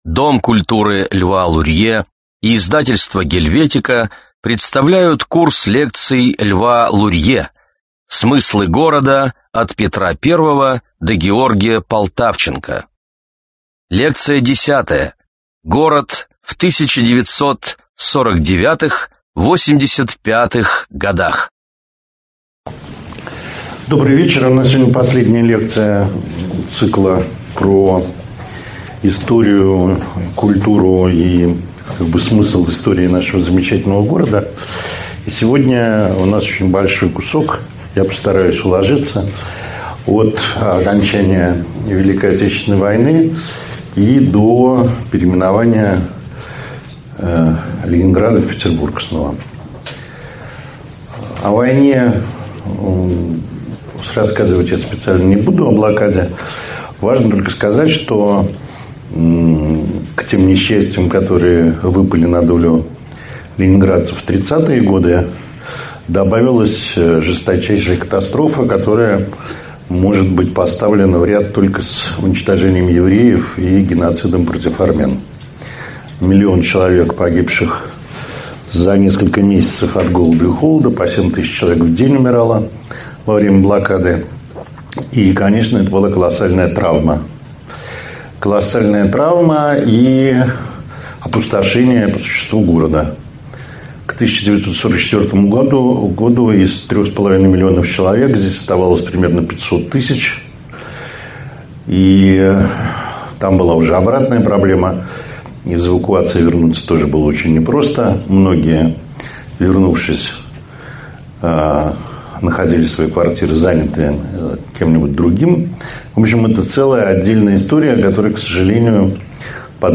Аудиокнига Лекция 10. Город в 1949-85 годах | Библиотека аудиокниг